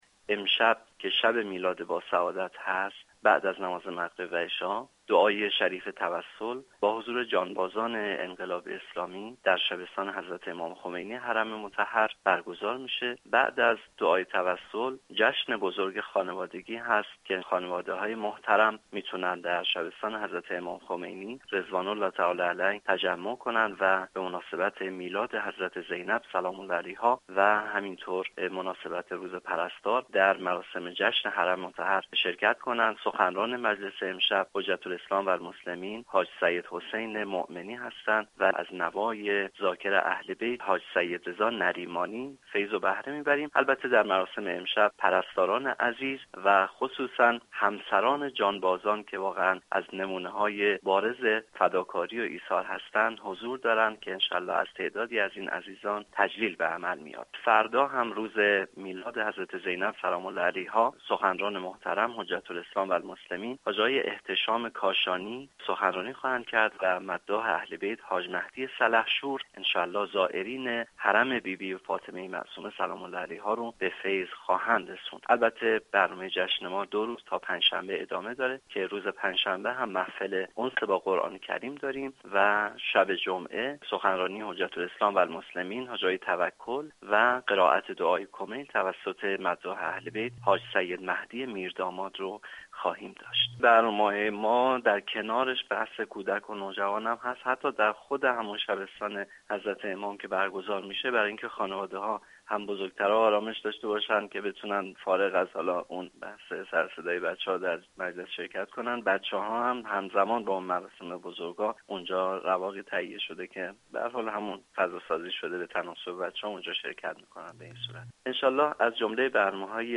در گفتگو با خبر رادیو زیارت ،درباره برنامه های پیش بینی شده برای امشب و فردا سخن گفت